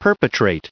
added pronounciation and merriam webster audio
2025_perpetrate.ogg